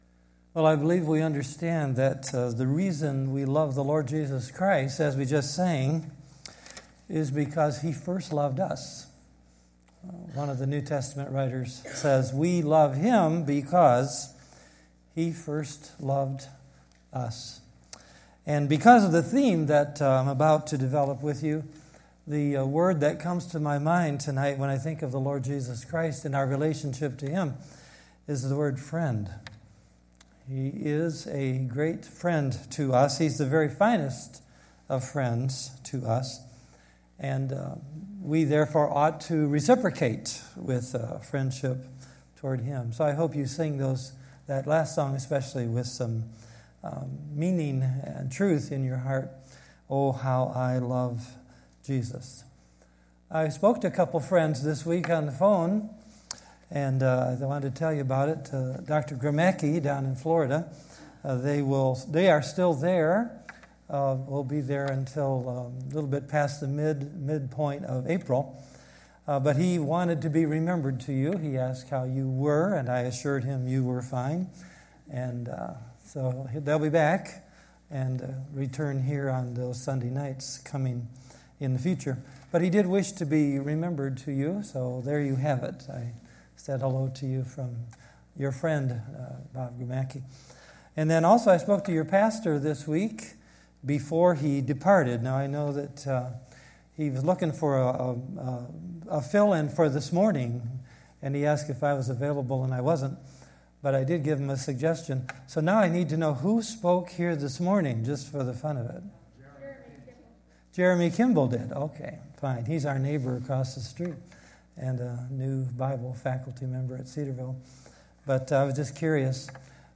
2016 Categories Sunday Evening Message Download Audio I Thessalonians 3:6-13 Previous Back Next